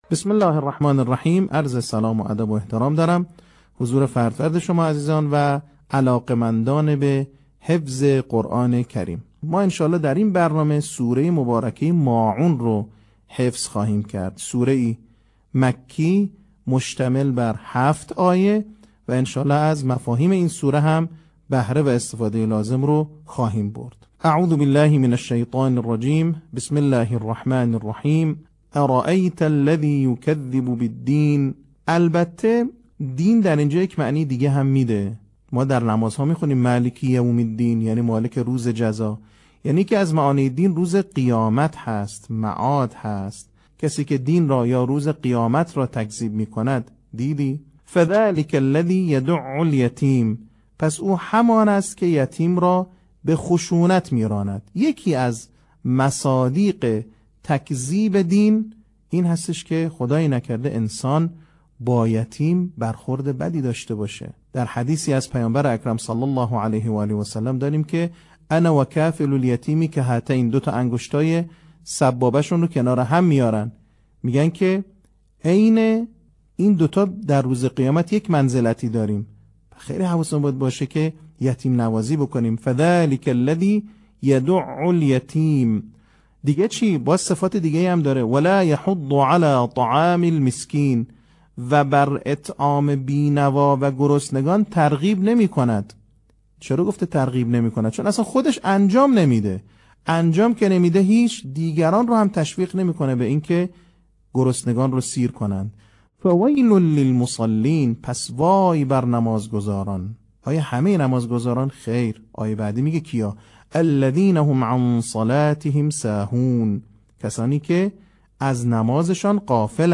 صوت | آموزش حفظ سوره ماعون